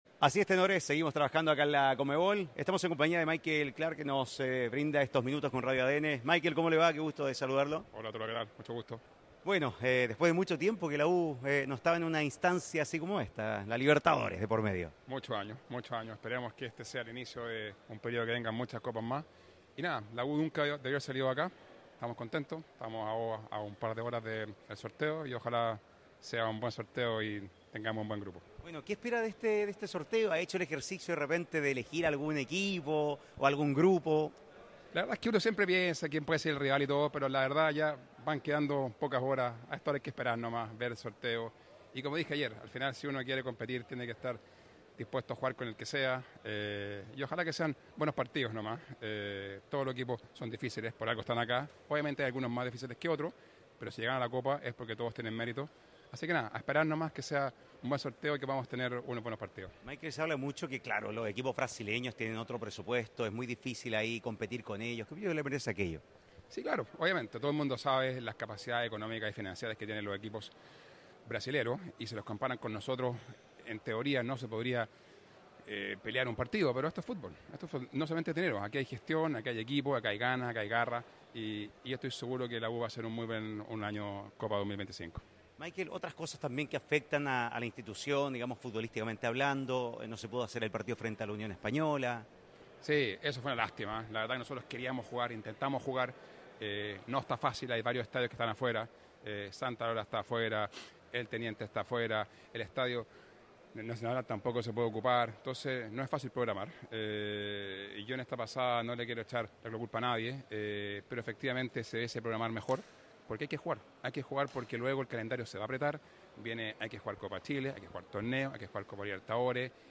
En conversación con ADN Deportes desde Asunción, también anticipó las expectativas de cara al sorteo por Copa Libertadores para la U de Chile.